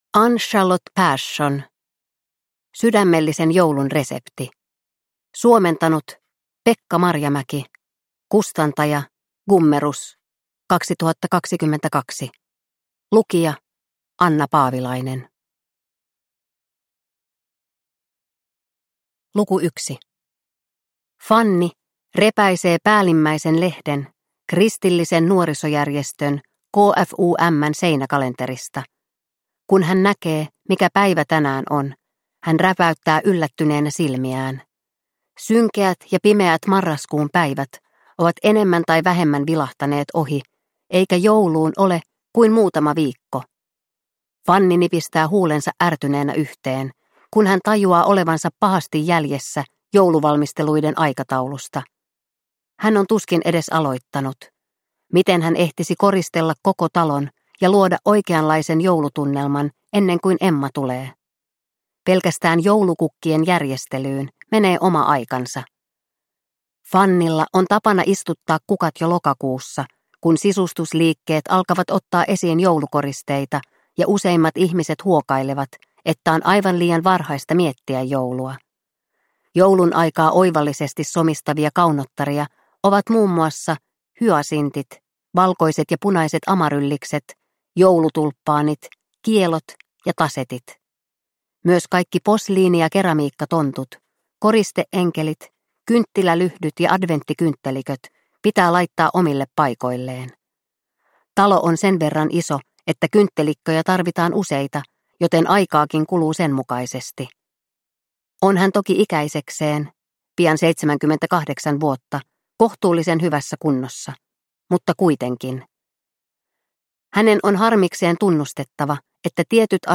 Sydämellisen joulun resepti – Ljudbok – Laddas ner